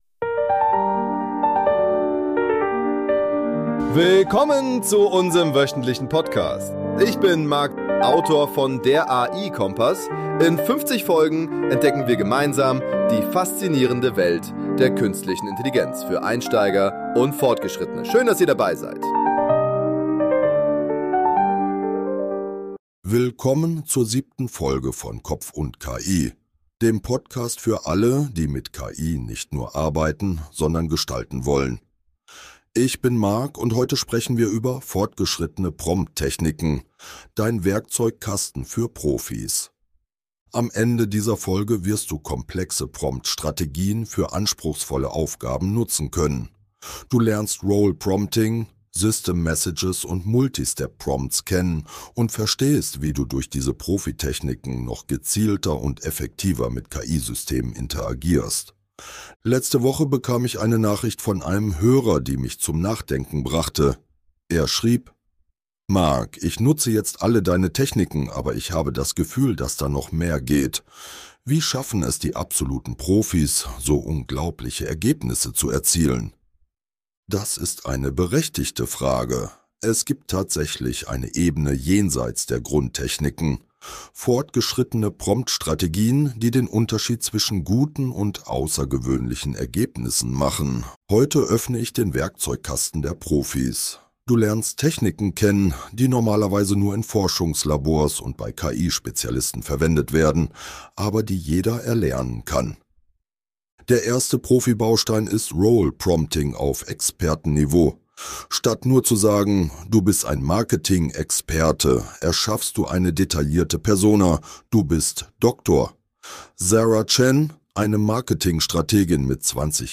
Du lernst Role Prompting, System Messages und Multi-Step Prompts kennen und verstehst, wie du durch diese Profi-Techniken noch gezielter und effektiver mit KI-Systemen interagierst. !!! Ab heute mit reparierter Stimme im KI Dialog !!!!